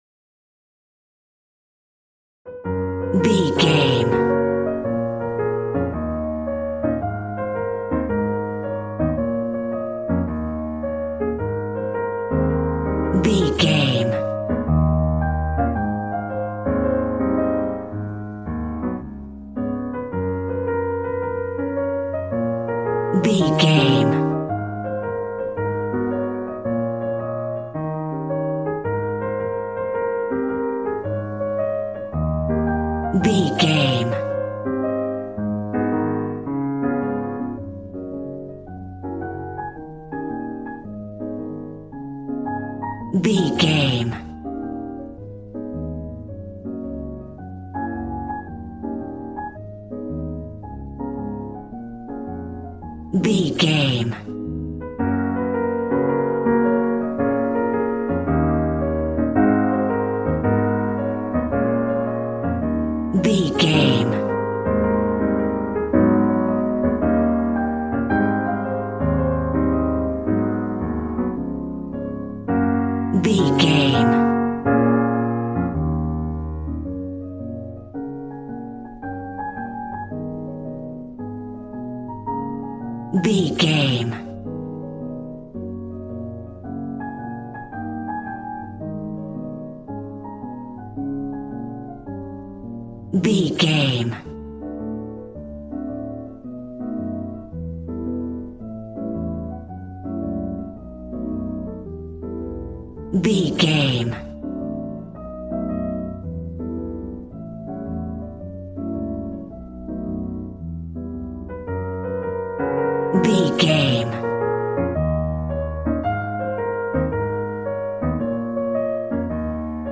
Aeolian/Minor
B♭
smooth
drums